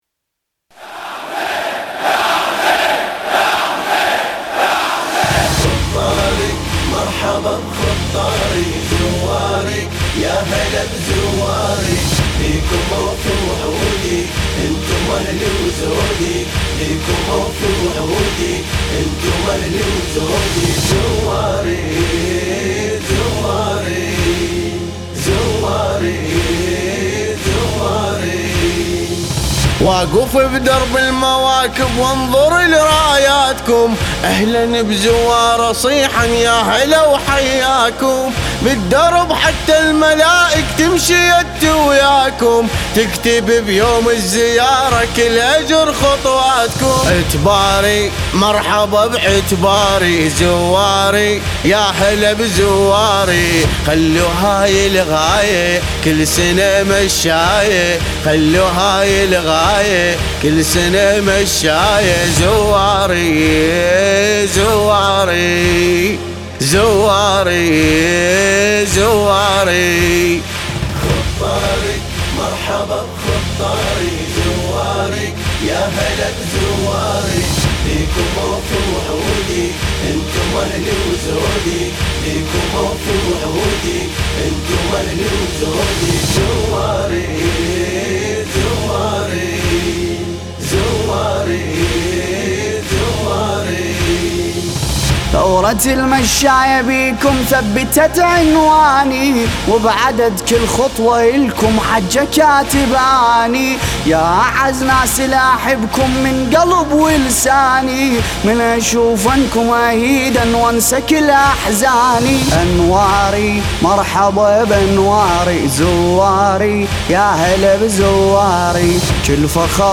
مداحی عربی
(ویژه اربعین)